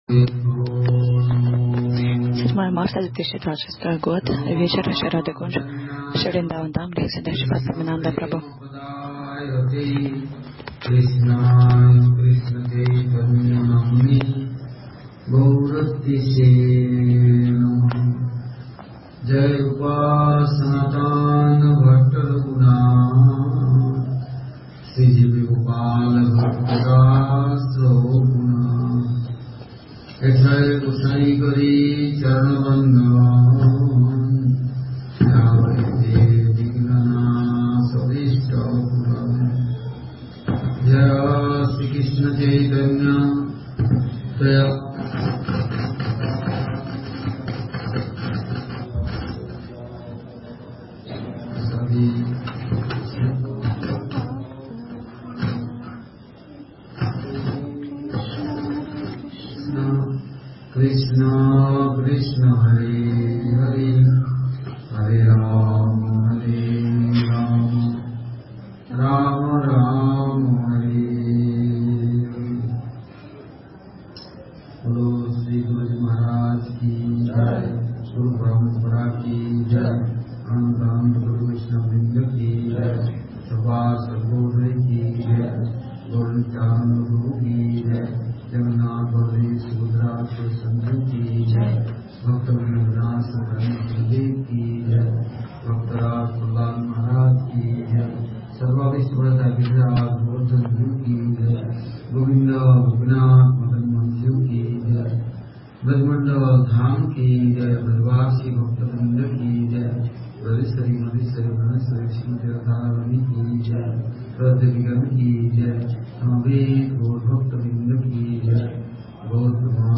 Вриндаван. Шри Радхе Кунджа. Бхакти Расамита Синдху